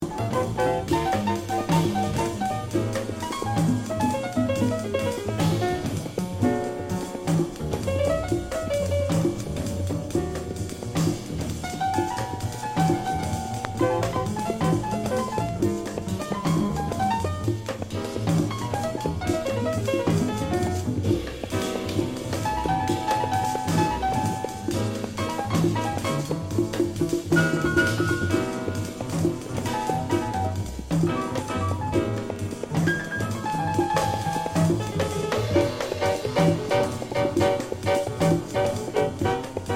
Tag       JAZZ OTHER